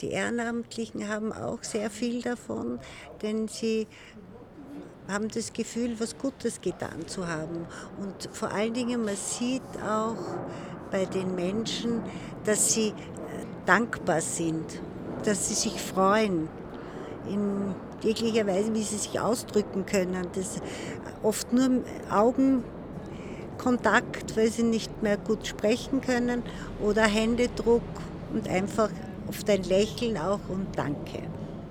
Pressegespräch: Einsamkeit aufspüren - Die stille Not lindern
O-Ton